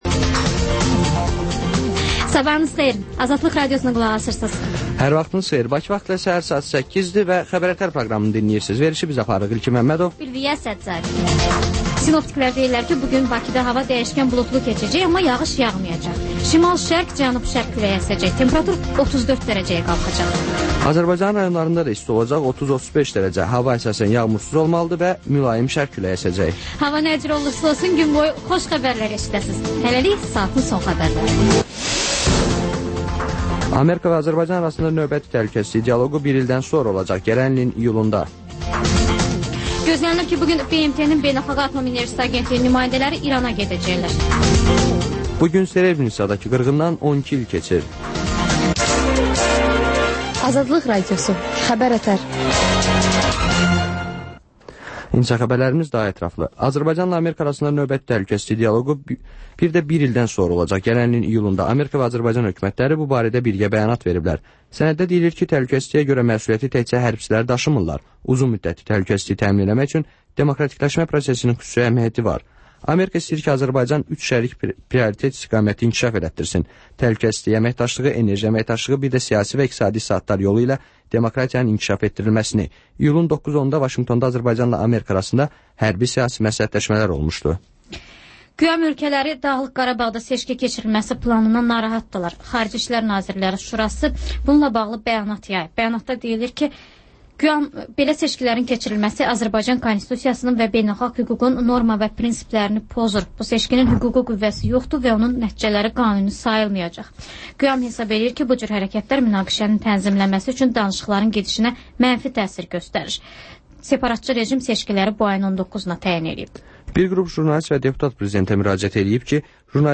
Xəbər-ətər: xəbərlər, müsahibələr və ŞƏFFAFLIQ: Korrupsiya haqqında xüsusi veriliş